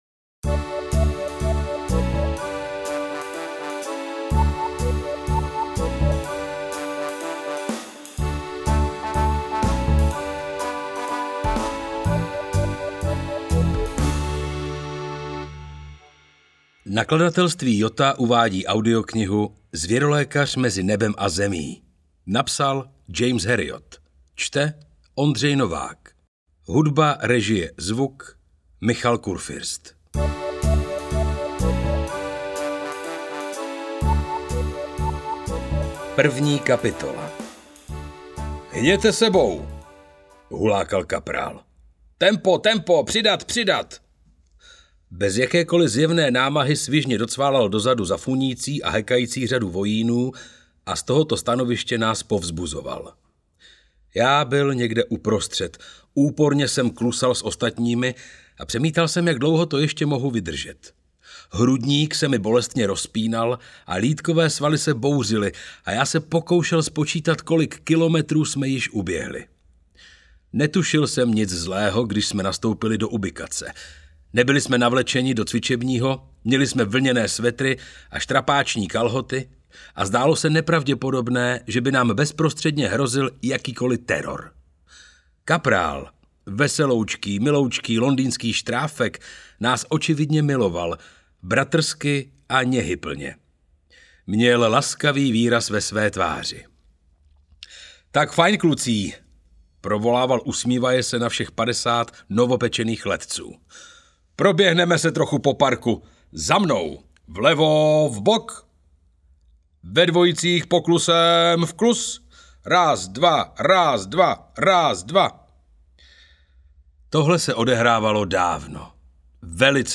Zvěrolékař mezi nebem a zemí audiokniha
Ukázka z knihy